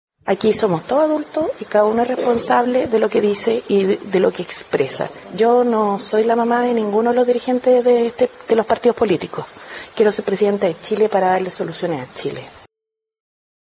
Desde la Región de Magallanes, en medio de su gira por el país, la abanderada del oficialismo hizo un llamado a su sector a concentrarse en la campaña y a entender que el adversario está al frente.